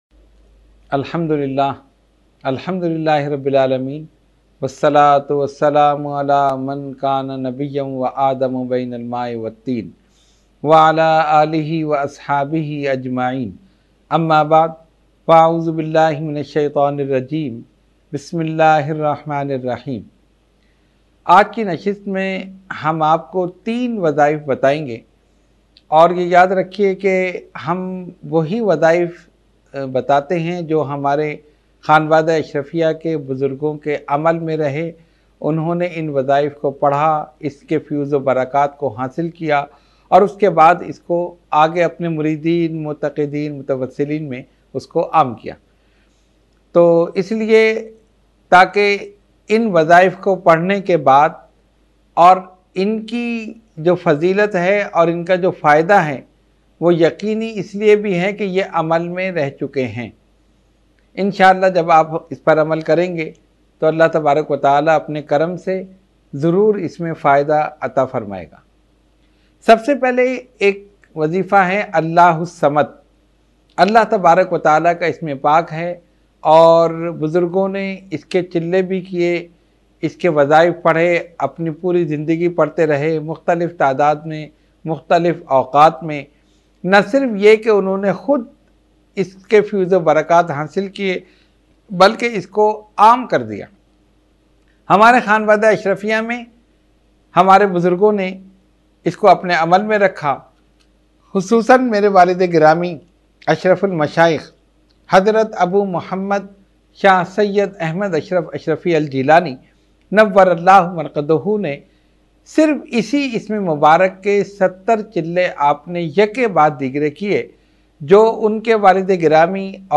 Roohani Tarbiyati Nashist held at Dargah Aliya Ashrafia Ashrafia Ashrafabad Firdous Colony Gulbahar Karachi.
Category : Speech | Language : UrduEvent : Weekly Tarbiyati Nashist